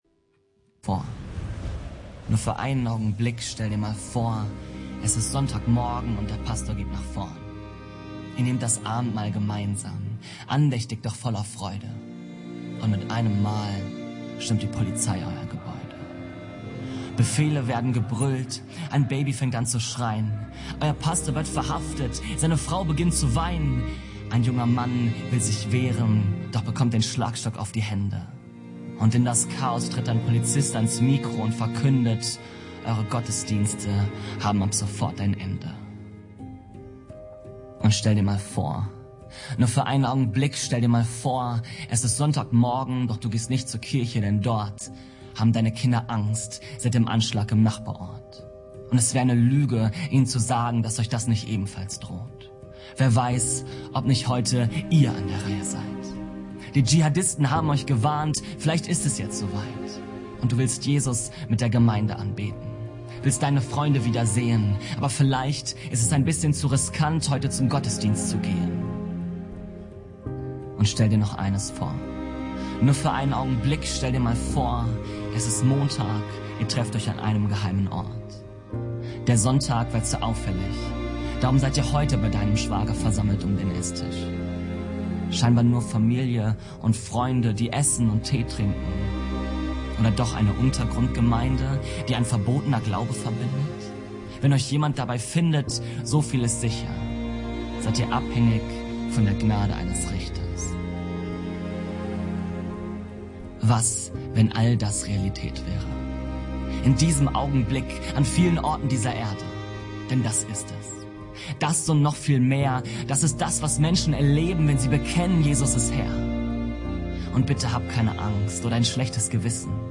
November 2024 Predigt Mit dem Laden des Videos akzeptieren Sie die Datenschutzerklärung von YouTube.